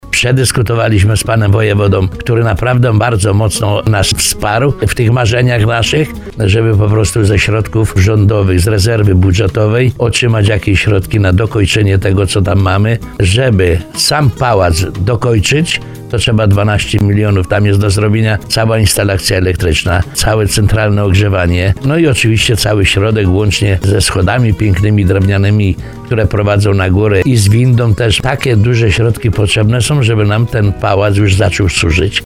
Gmina liczy na rządowe wsparcie, bo do zakończenia prac potrzeba co najmniej 12 milionów złotych. Burmistrz Wojnicza Tadeusz Bąk, który był gościem porannej rozmowy Słowo za Słowo zapewnił, że rozmawiał już w tej sprawie z wojewodą małopolskim.